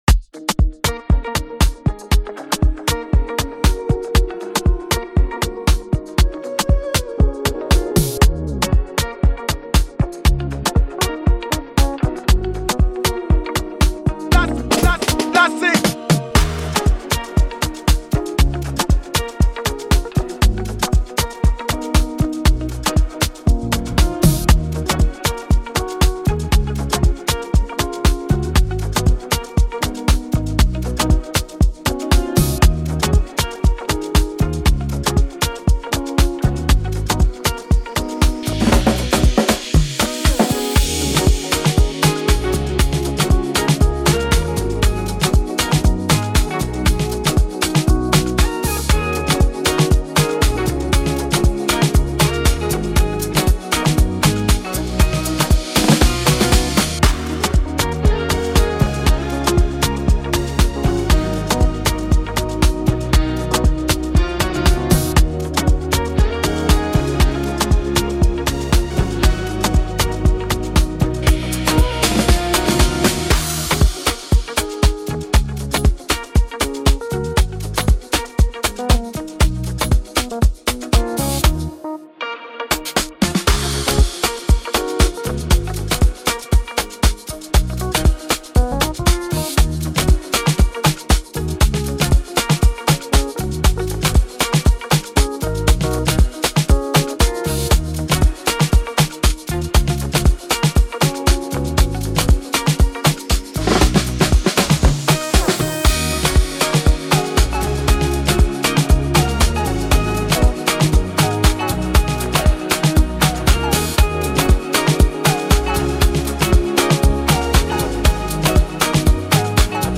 Beat Type: Afrobeat